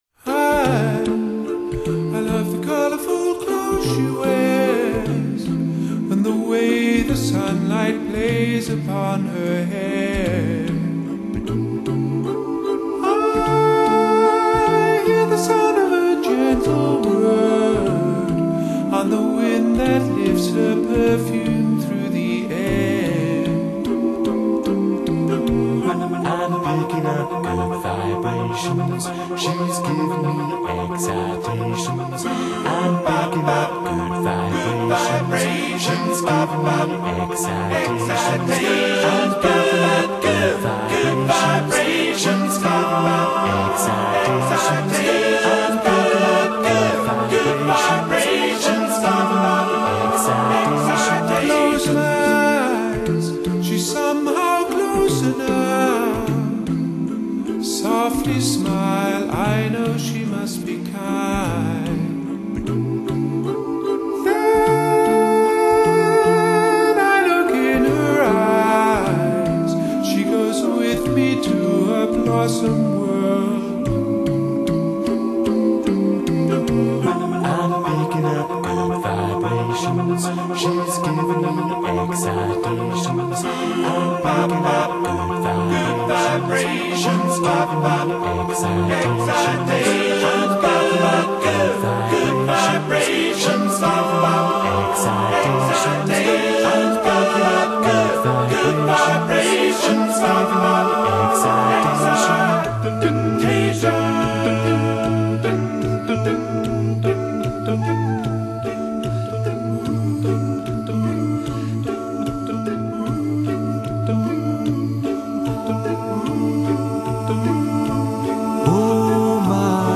高音绕梁的本事，乍听之下以为是女高音引喉飙窜；男低音则作鼓声隆隆，引导音乐以各种节奏向前行进
他们音色纯净和谐，声部平衡，和声精妙
六人團體 六口就是樂器 謝謝即往的介紹
A capella 无乐器伴奏, 人声清唱~~音韵十足~~